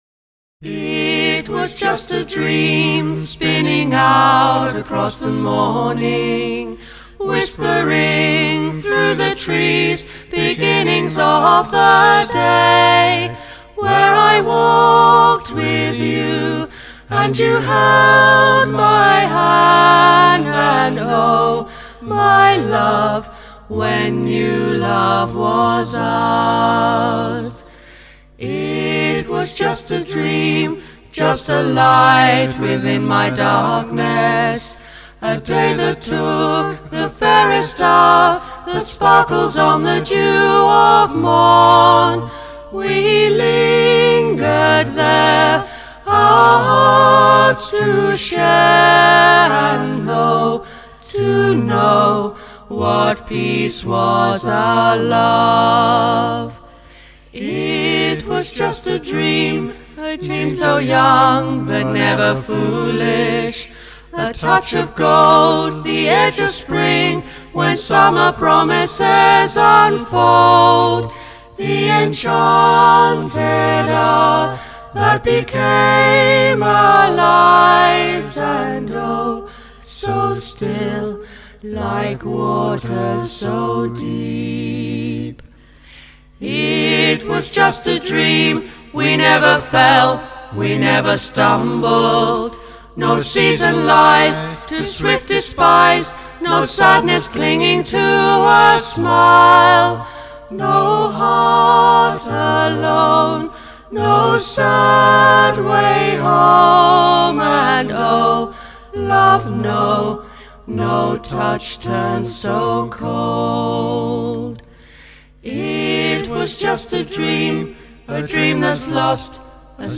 Recorded at East Surrey College